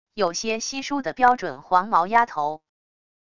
有些稀疏的标准黄毛丫头wav音频